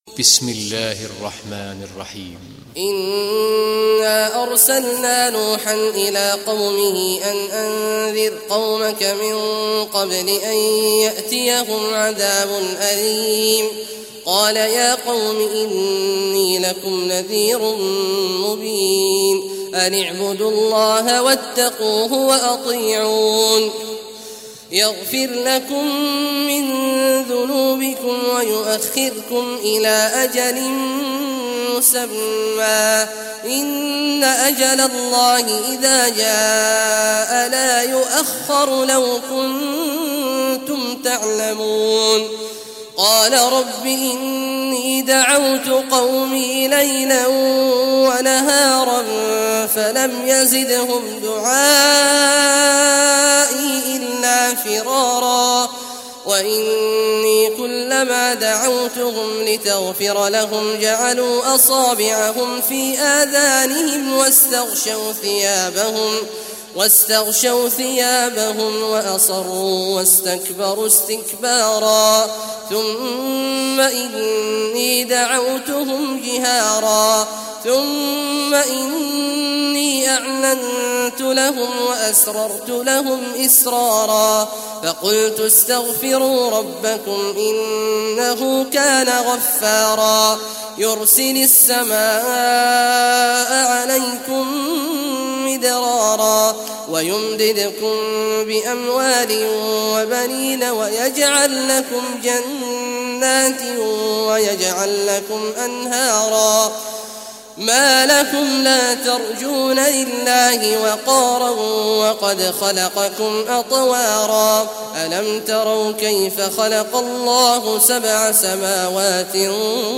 Surah Nuh Recitation by Sheikh Awad al Juhany
Surah Nuh, listen or play online mp3 tilawat / recitation in Arabic in the beautiful voice of Sheikh Abdullah Awad al Juhany.